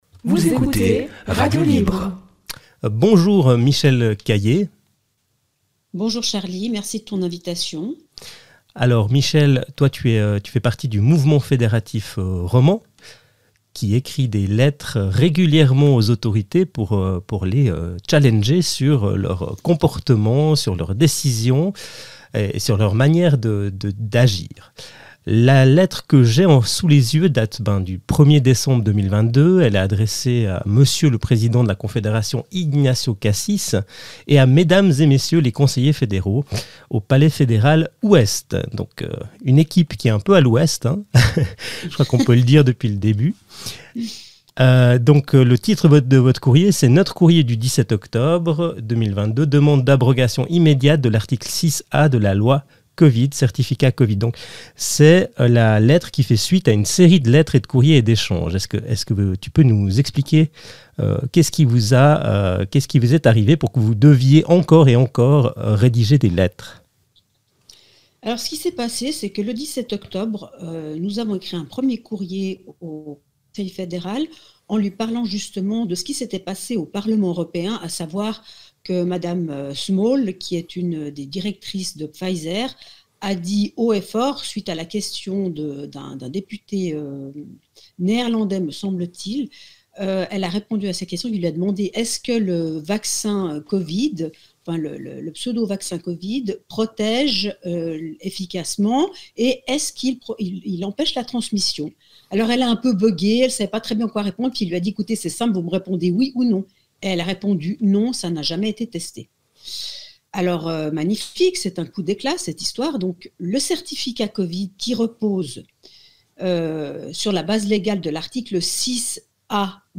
Le Mouvement Fédératif Romand (MFR) n'en démord pas, il faut abroger la loi Covid au plus vite. Interview